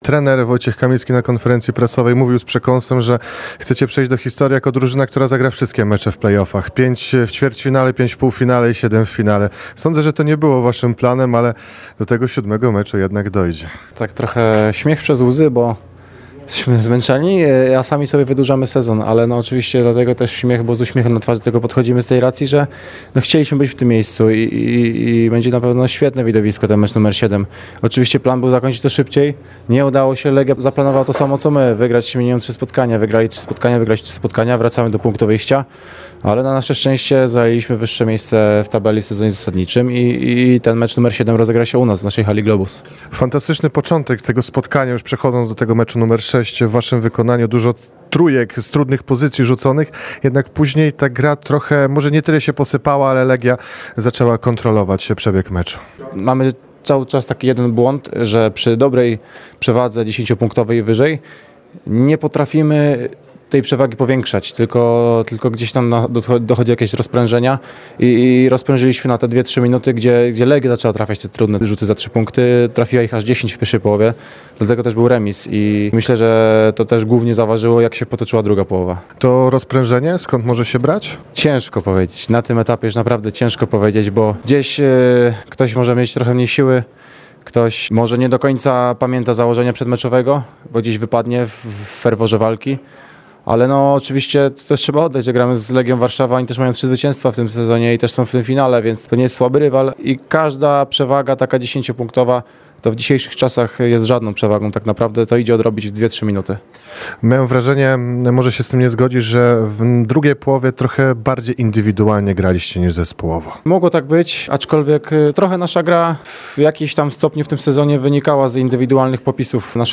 Cała rozmowa w materiale dźwiękowym: Siódmy decydujący mecz zostanie rozegrany w hali Globus.